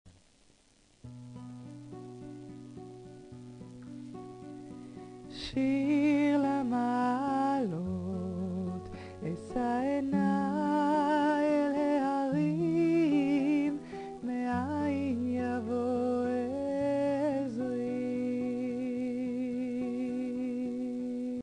Druze group started to speak Hebrew and gathered around the grand piano in the piano bar to sing in English